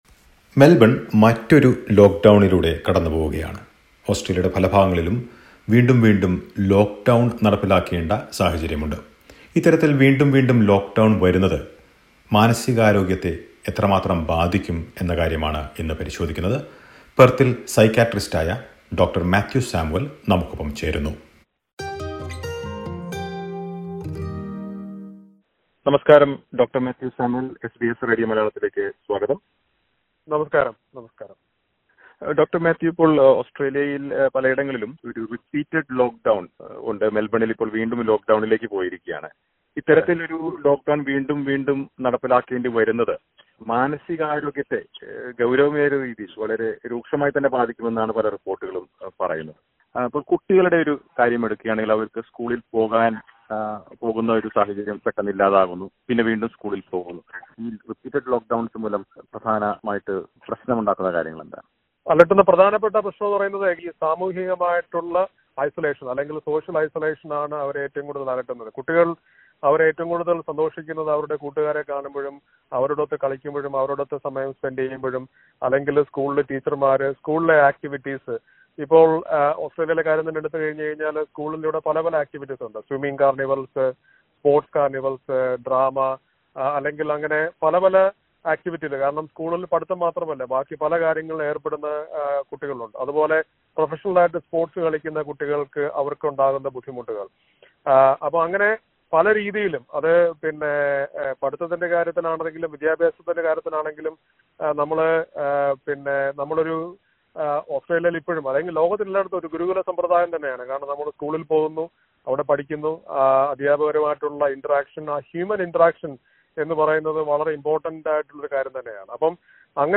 Disclaimer: The information provided in this interview is general in nature.